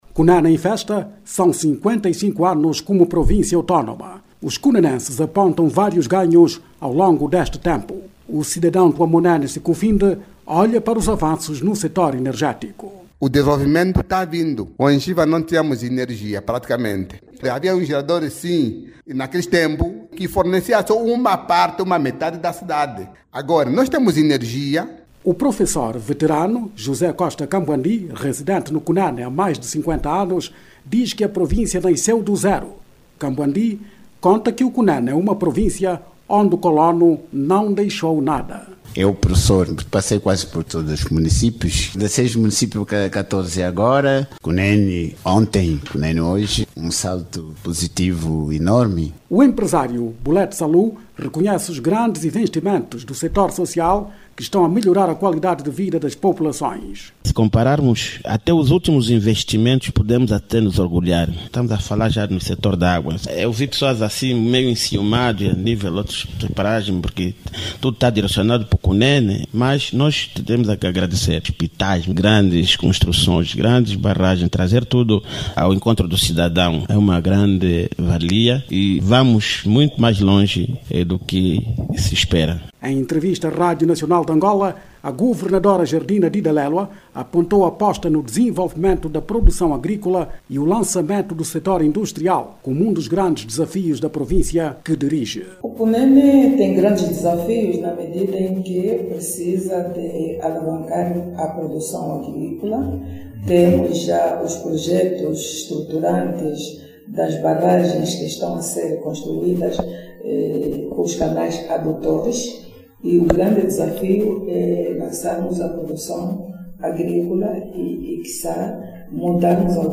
A província do Cunene, esta a completar hoje, quinta-feira(10), cinquenta e cinco anos desde que foi elevado a categoria de cidade. Habitantes do Cunene, descrevem ganhos no tocante ao desenvolvimento e crescimento.